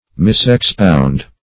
Misexpound \Mis`ex*pound"\